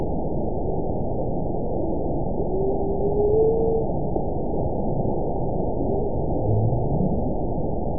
event 922039 date 12/26/24 time 00:06:34 GMT (5 months, 3 weeks ago) score 9.17 location TSS-AB02 detected by nrw target species NRW annotations +NRW Spectrogram: Frequency (kHz) vs. Time (s) audio not available .wav